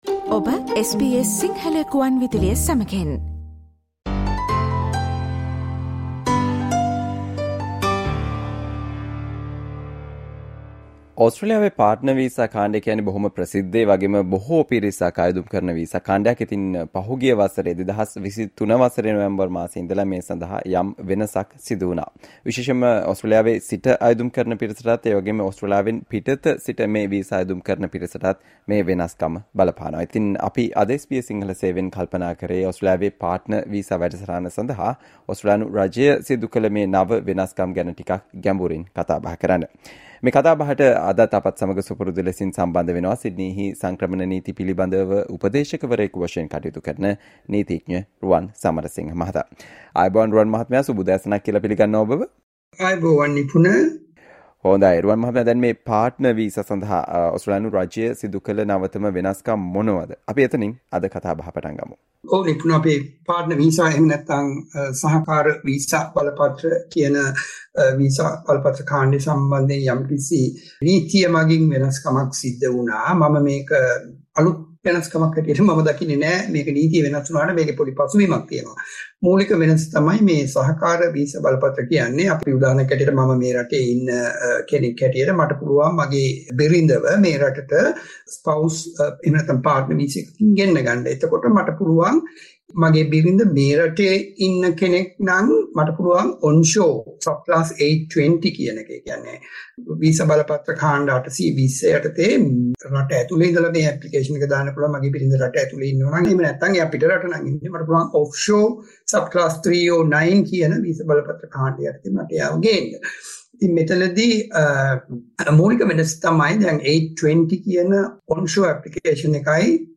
SBS Sinhala discussion on Important information about the new changes announced to Australia's Partner visa program